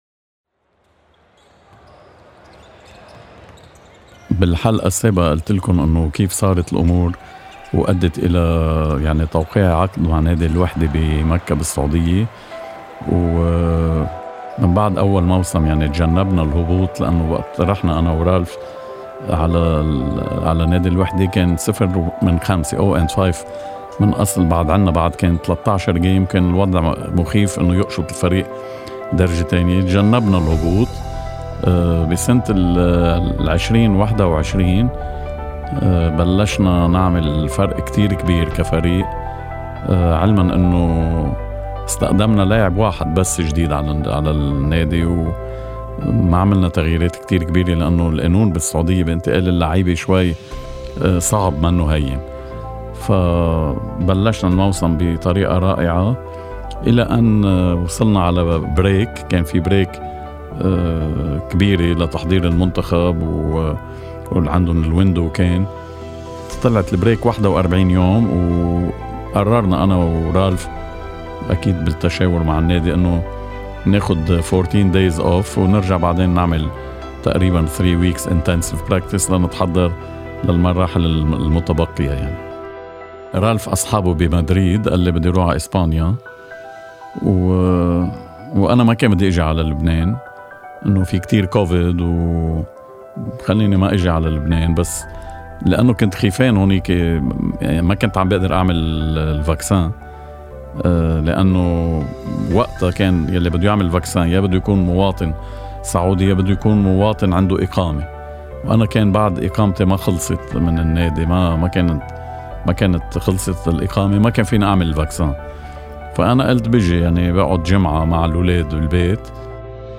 غسان سركيس هو من أبرز مدربي كرة السلة اللبنانية، سنتعرّف على قصة حياته كاملة وتفاصيل كثيرة يرويها غسان بنفسه في هذا البودكاست